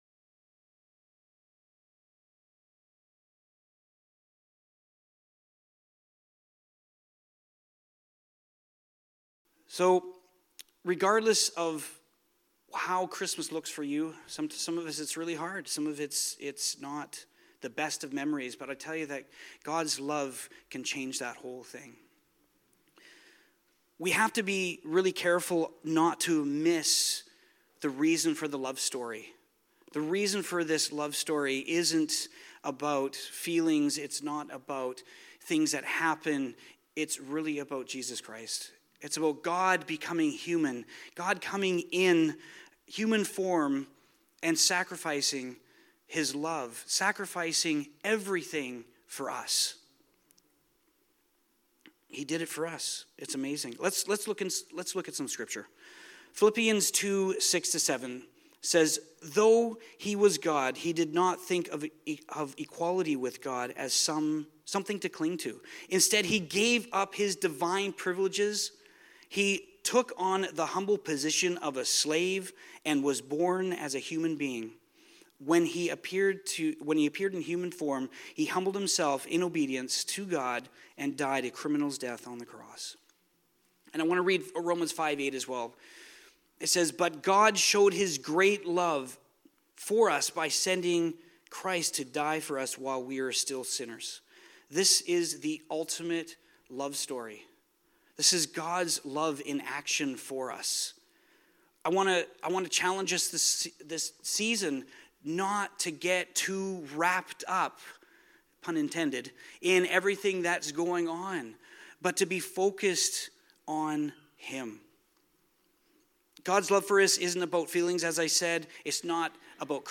Sermons | City Light Church